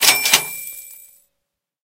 snd_buyForCoins.ogg